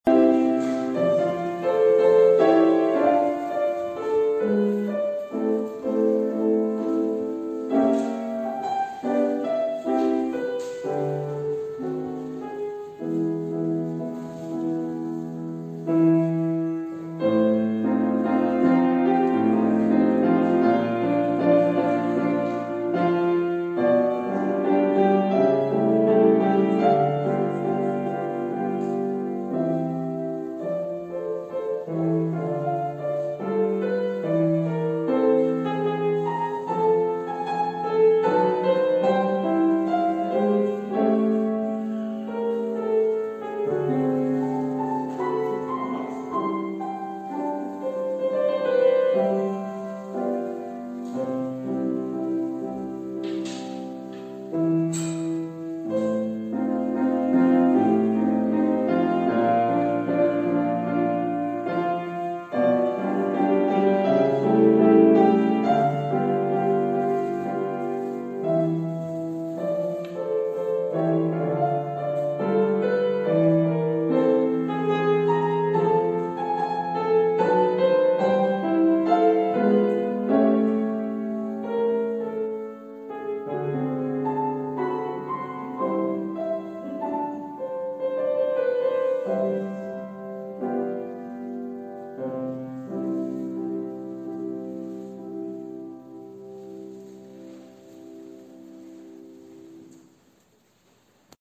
Online-Gottesdienst zum Feiern zu Hause
aus der reformierten Erlöserkirche am 28. August 2022
Präludium